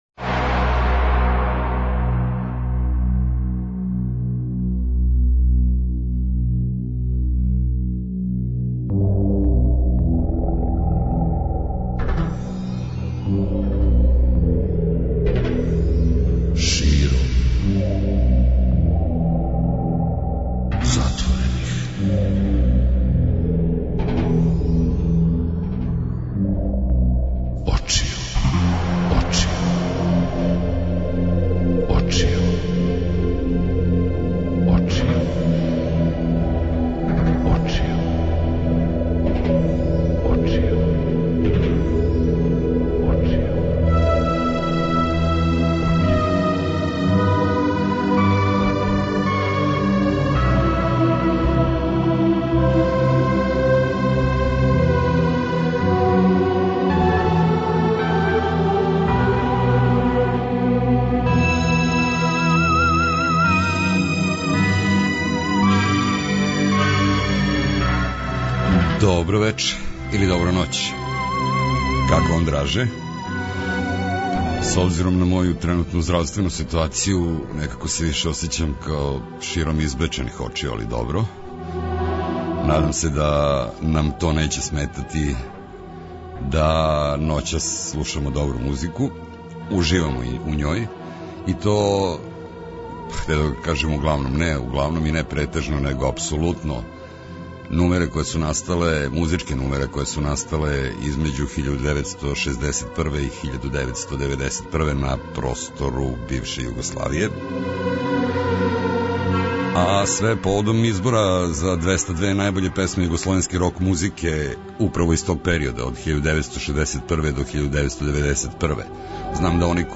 У ноћи између среде и четвртка, од поноћи до четири ујутру, слушамо музику са простора некадашње Југославије и подсећамо се песама које су обележиле период од 1961. до 1991. године.